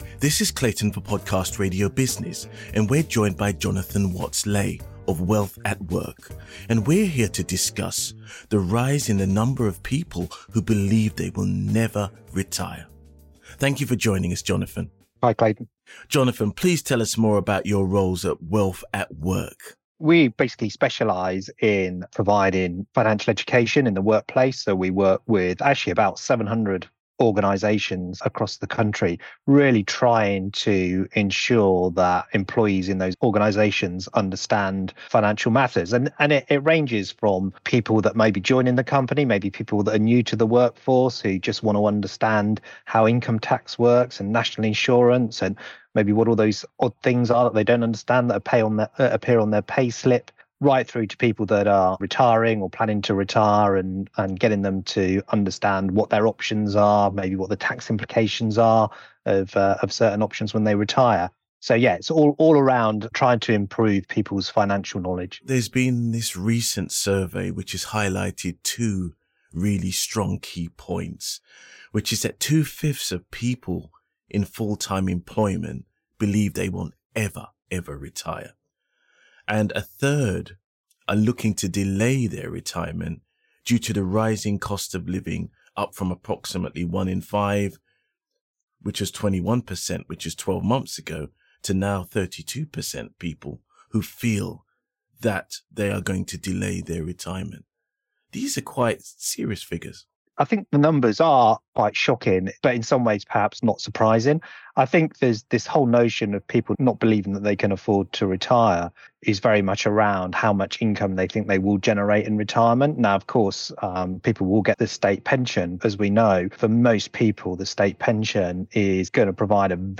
Podcast Interview – The Cashflow Show.